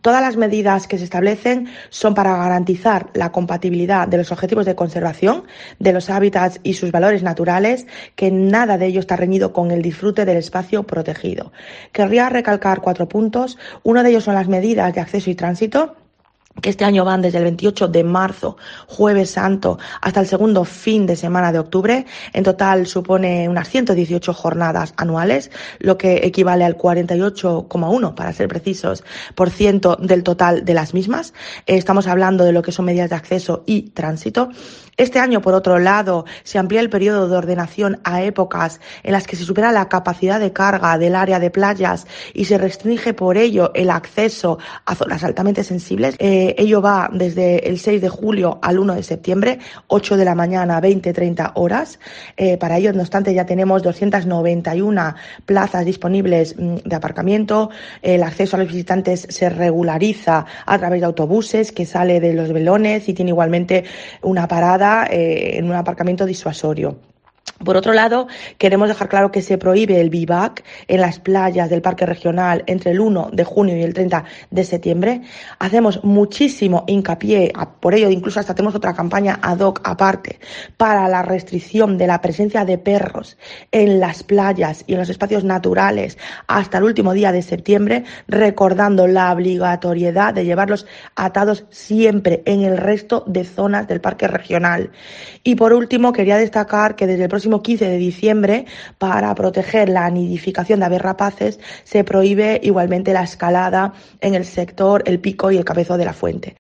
María Cruz Ferreira, secretaria autonómica de Energía, Sostenibilidad y Acción Climática